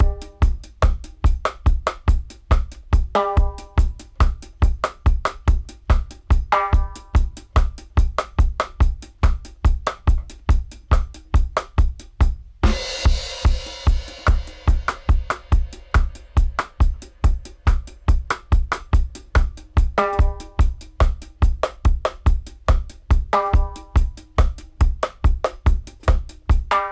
142-DRY-02.wav